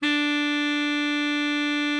bari_sax_062.wav